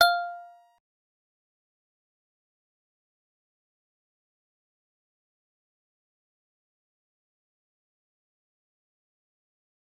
G_Musicbox-F5-pp.wav